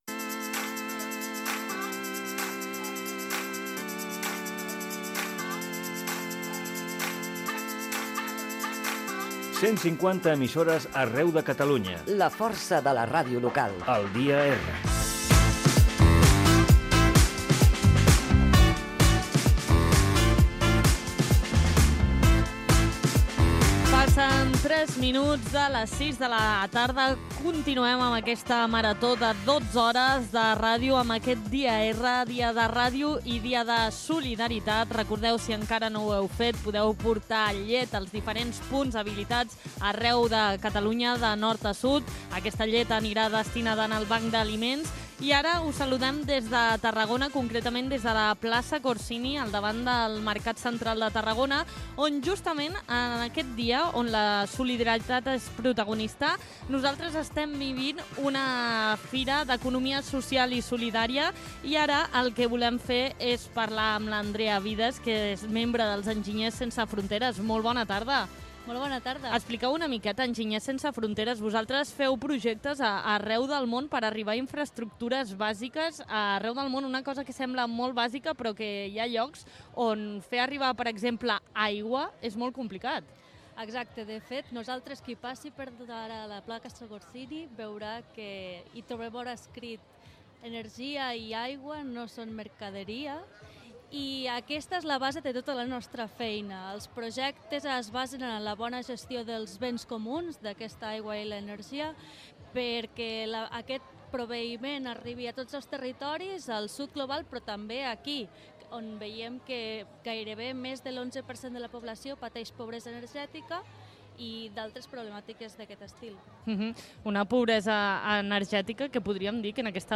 Indicatiu del programa. Fragment fet des de la Plaça Corsini de Tarragona.
Divulgació